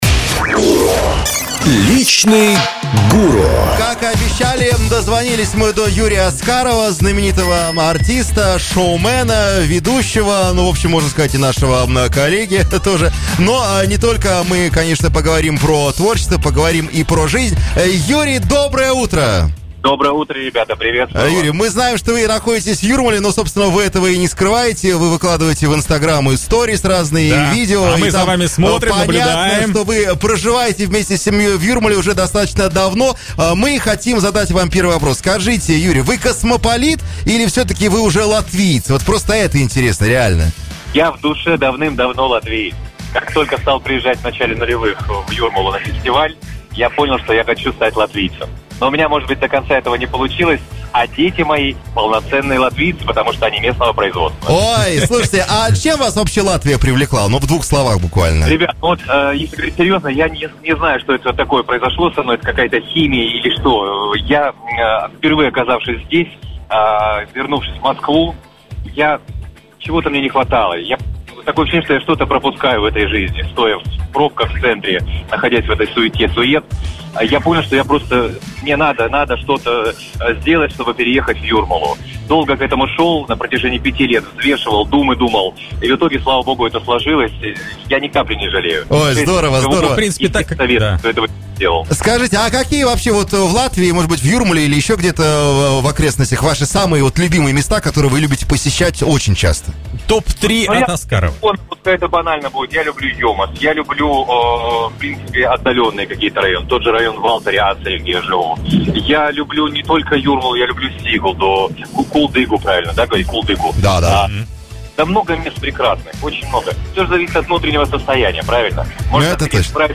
У нас на связи знаменитый юморист, шоумен и ведущий Юрий Аскаров: как московские пробки привели его в Юрмалу, чем его привлекает Латвия, почему артистам не хватает атмосферы юрмальских фестивалей и каким образом видеокассета с его выступлением попала к мистеру Бину.